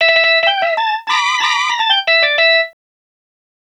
Swinging 60s 4 Organ Lk-E.wav